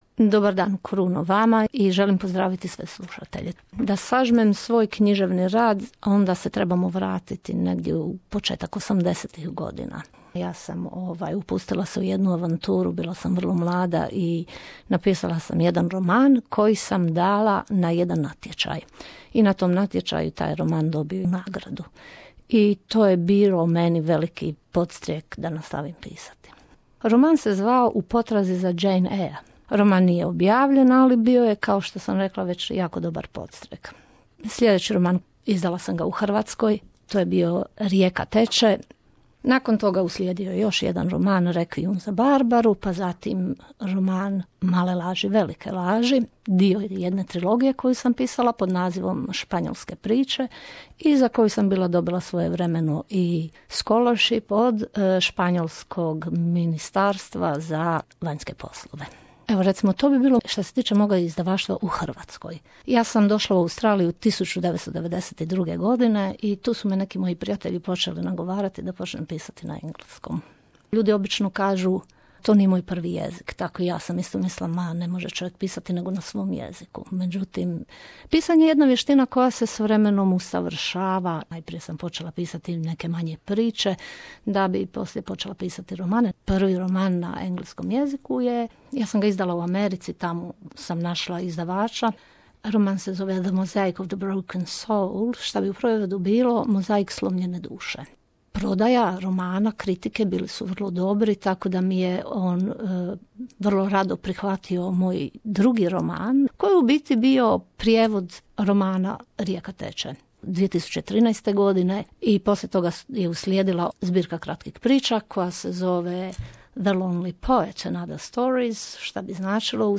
Razgovor je započeo osvrtom na dosadasnju karijeru te temama do sada objavljenih djela.